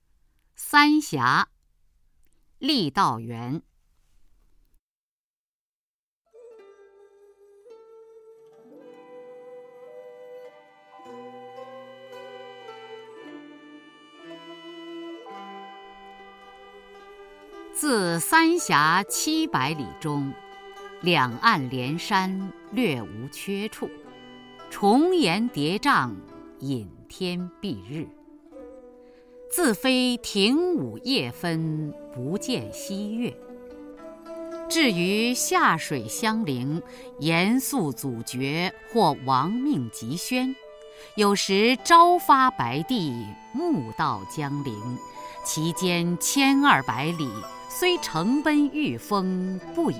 初中生必背古诗文标准朗诵（修订版）（1）-08-雅坤-三峡 《水经注 江水》北魏 郦道元